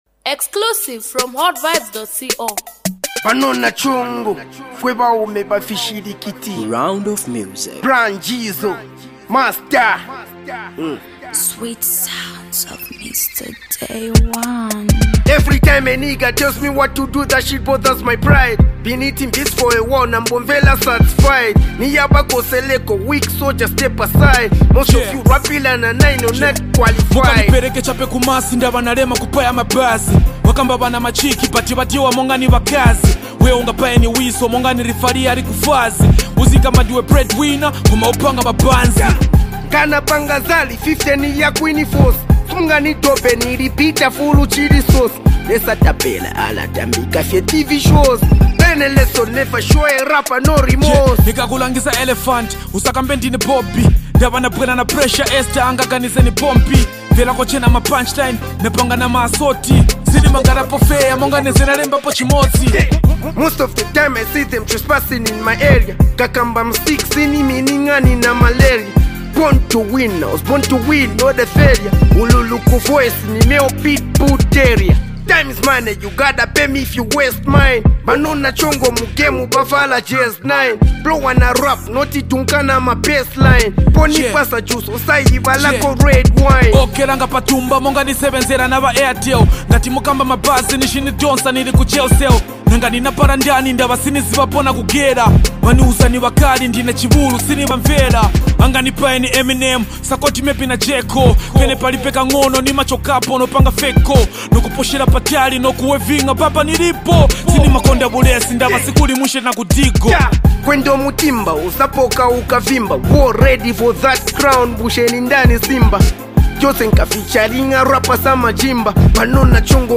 Talented rapper based in lusaka